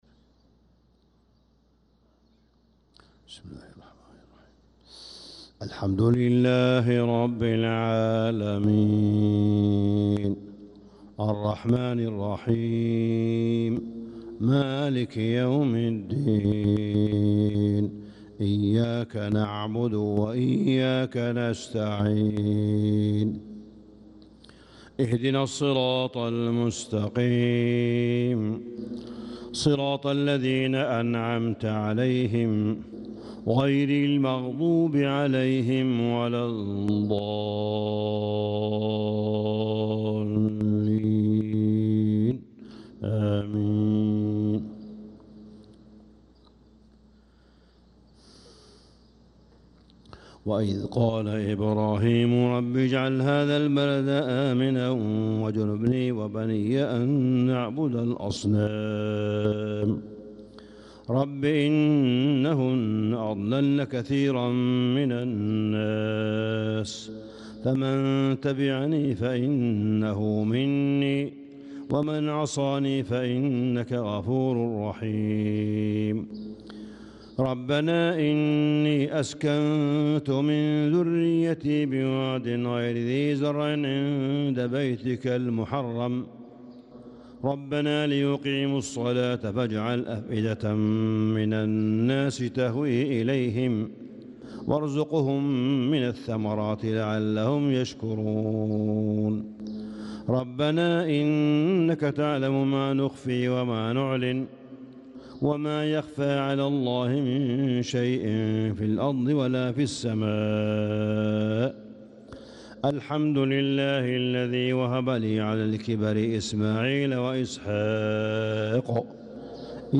صلاة الفجر للقارئ صالح بن حميد 4 ذو الحجة 1445 هـ
تِلَاوَات الْحَرَمَيْن .